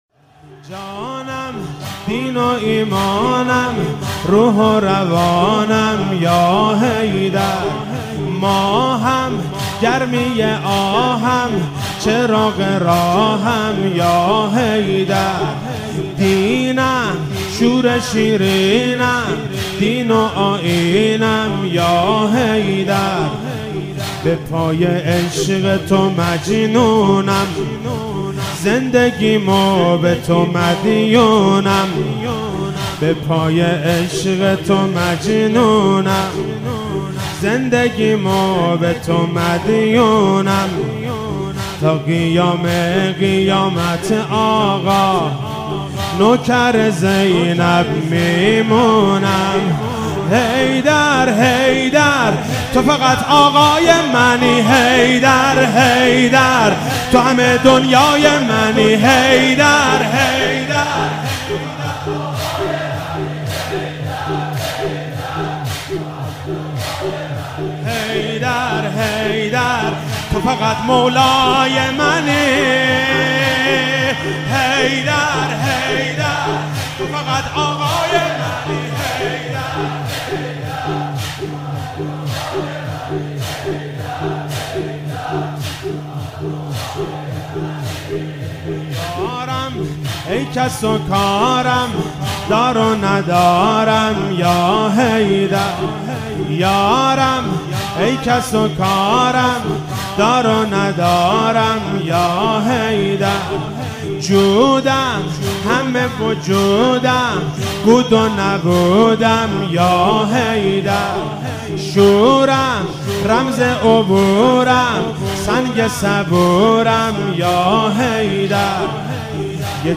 شب 21 ماه مبارک رمضان 96(قدر) - زمینه - جانم روح و ایمانم روح و روانم